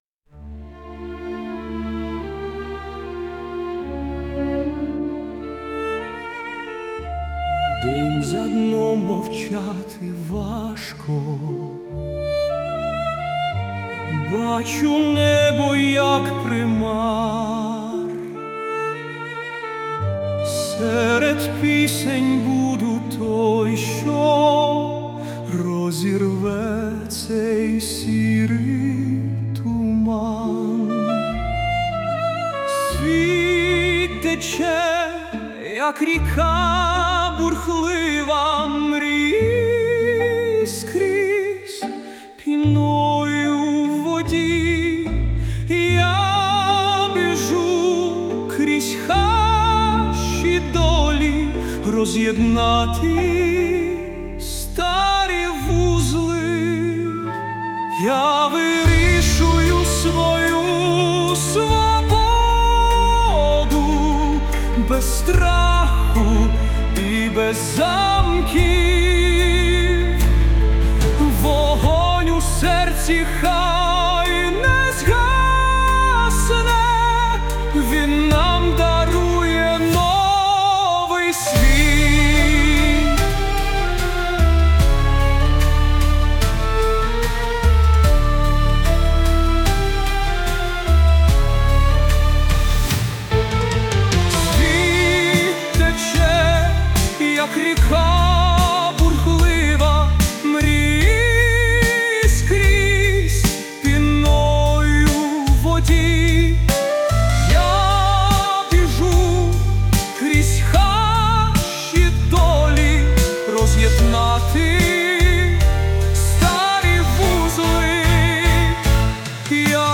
With Vocals / 歌あり
新体操では珍しい、圧倒的な歌唱力を持つ男性ボーカルのバラードです。
魂を揺さぶるようなドラマチックな歌声は、フロアを一つの劇場のような空間へと変えます。